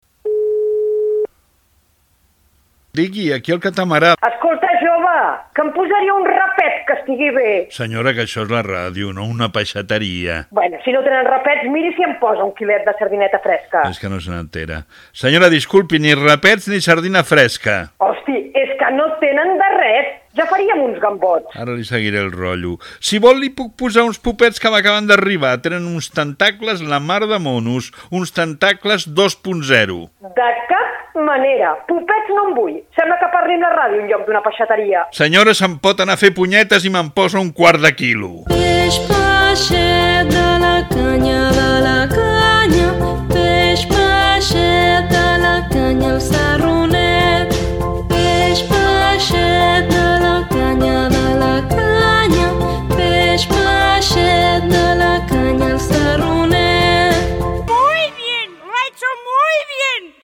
Gag del programa, amb una dona que parla des d'un faristol.
Entreteniment